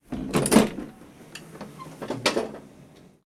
ventilar ventana abrir apertura cerradura chasquido cierre golpe manilla mecanismo ruido sonido
Sonidos: Hogar